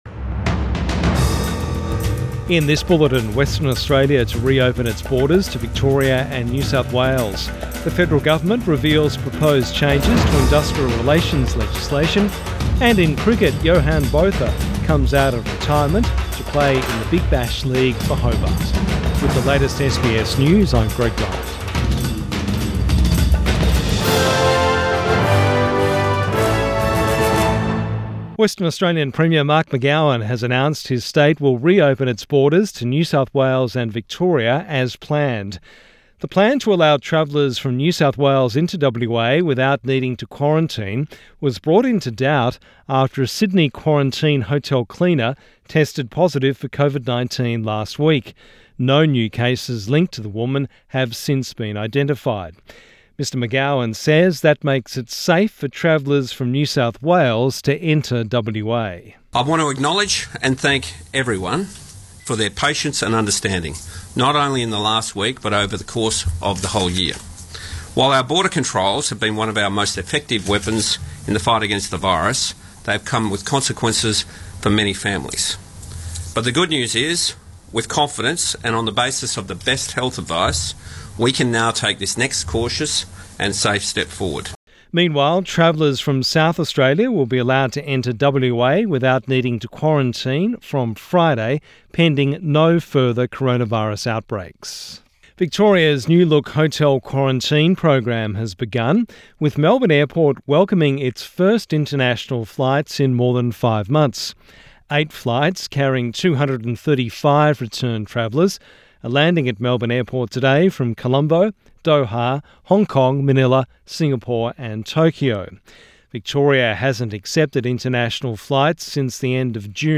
PM bulletin 7 December 2020